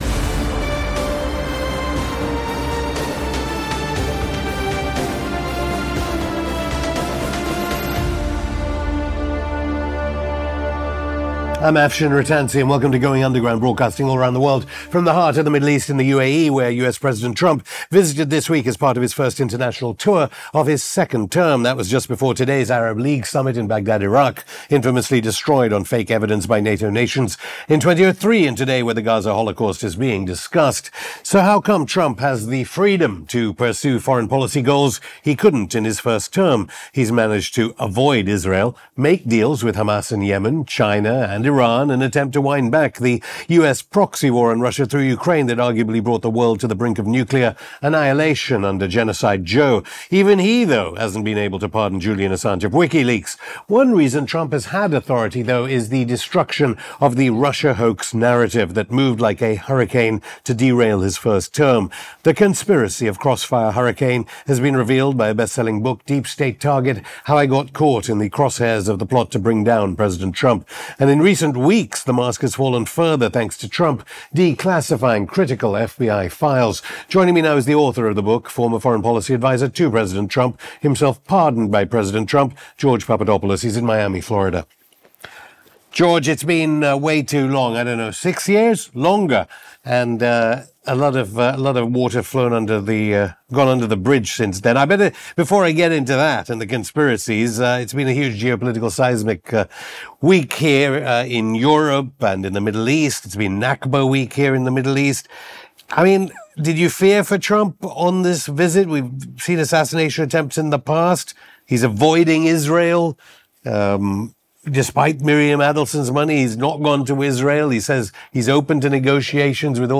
Trump's SLAM DUNK Middle East Trip & The Sabotage Campaign Against Trump (George Papadopoulos) (Afshin Rattansi interviews George Papadopoulos; 17 May 2025) | Padverb